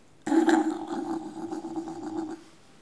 ajout des sons enregistrés à l'afk ...